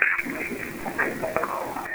Common EVP Phrases
Are Phrases We Often Hear When Recording EVP
helpmeImcold.wav